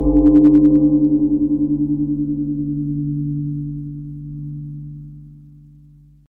Carmen Distortion Bell.